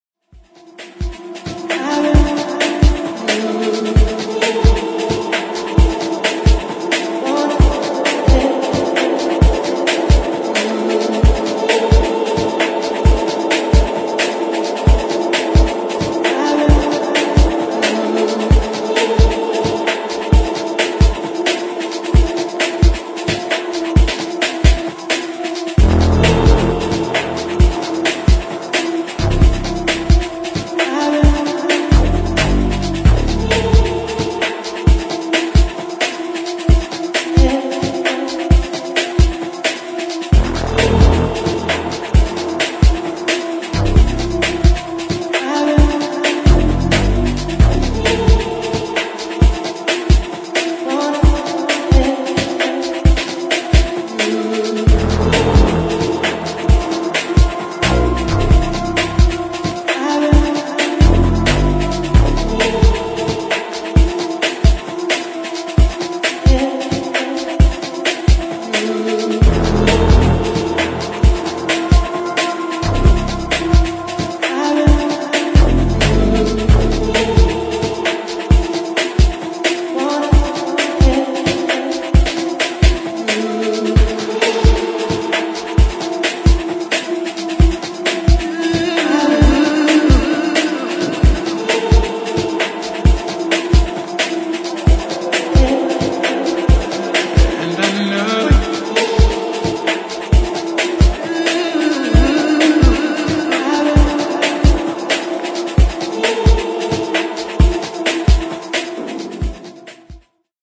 [ TECH HOUSE ]